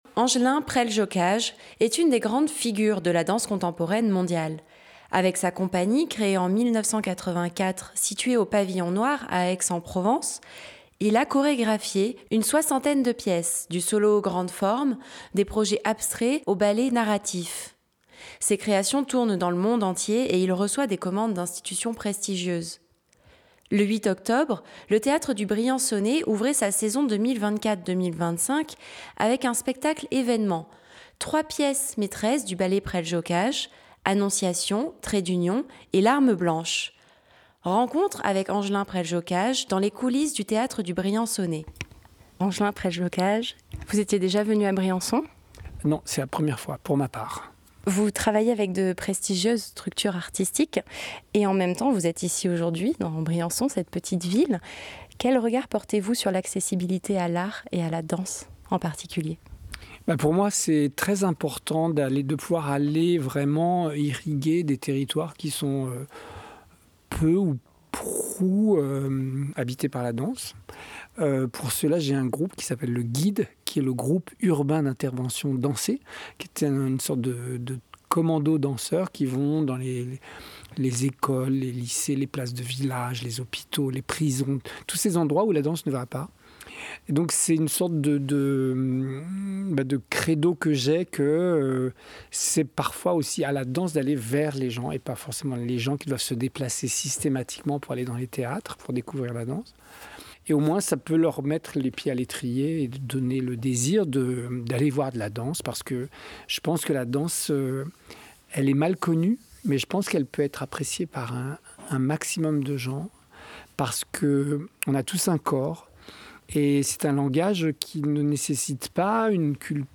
Le 8 octobre, le Théâtre du Briançonnais ouvrait sa saison 2024-2025 avec un spectacle événement : 3 pièces maîtresses du ballet Preljocaj : Annonciation, Trait D'union et Larmes Blanche. Rencontre avec Angelin Preljocaj dans les coulisses du TDB.